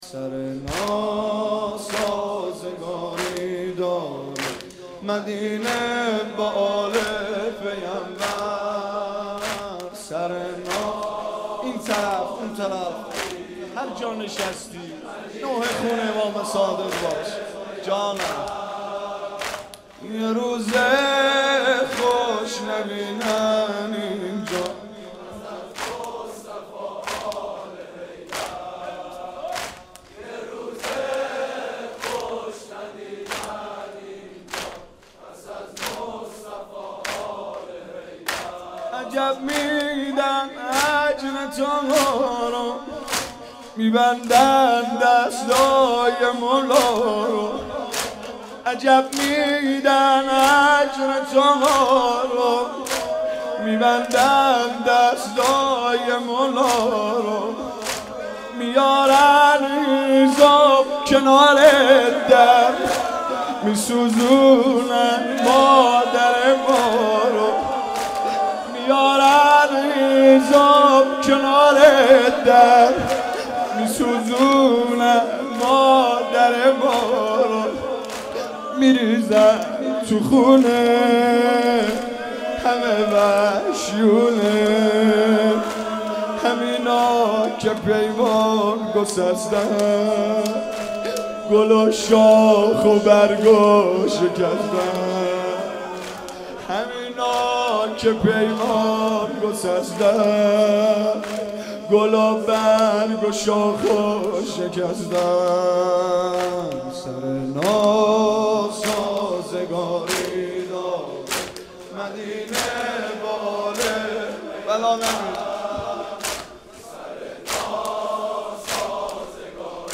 گلچین مداحی های ایام شهادت امام صادق(ع)